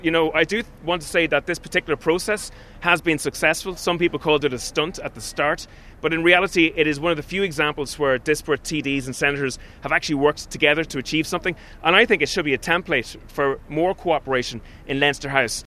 Aontú leader Peadar Tóibín is appealing for two more TDs or Senators to support Maria Steen: